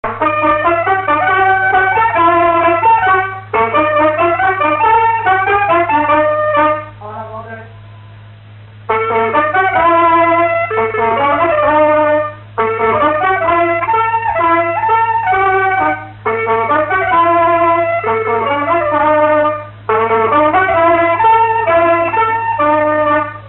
En avant deux
clarinette
Trois avant-deux au piano, et un quadrille aux cuivres
Pièce musicale inédite